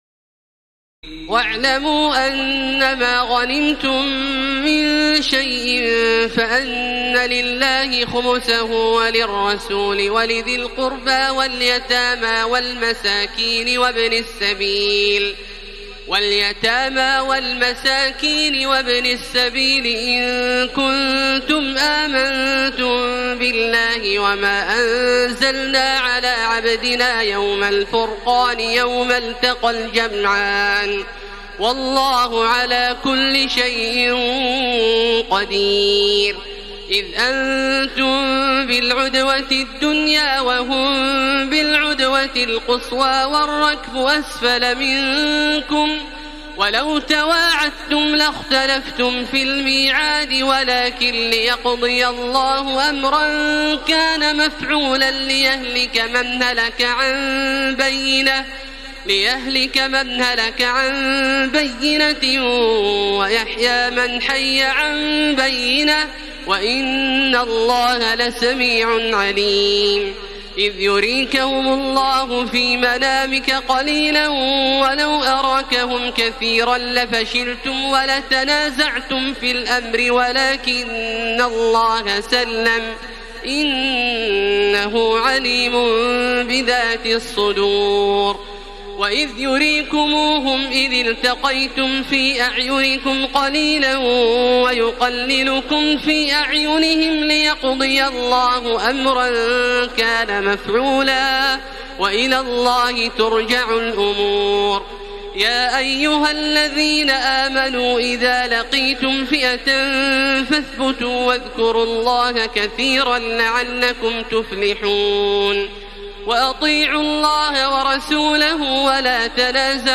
تراويح الليلة التاسعة رمضان 1433هـ من سورتي الأنفال (41-75) و التوبة (1-33) Taraweeh 9 st night Ramadan 1433H from Surah Al-Anfal and At-Tawba > تراويح الحرم المكي عام 1433 🕋 > التراويح - تلاوات الحرمين